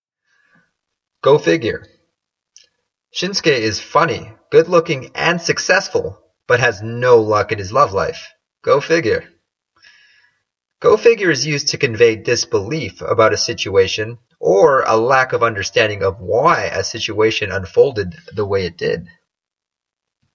本当に不思議だ」 英語ネイティブによる発音は下記のリンクをクリックしてください。